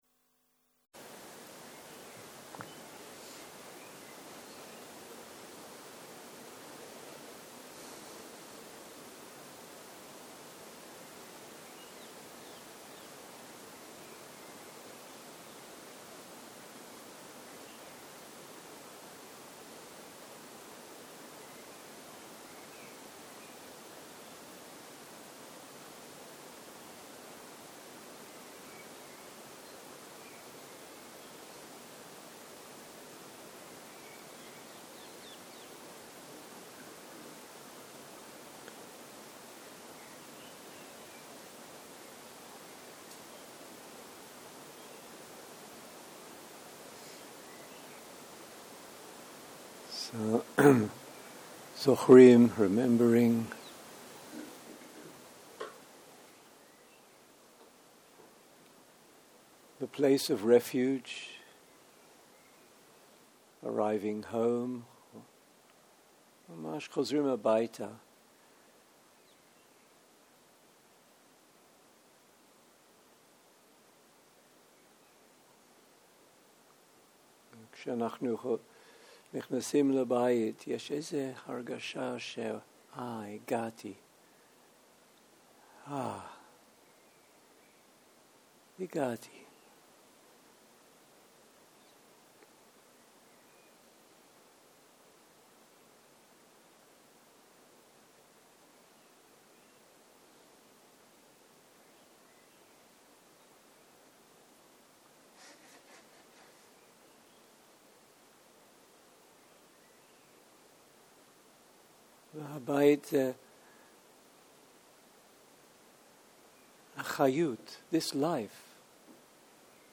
מדיטציה מונחית שפת ההקלטה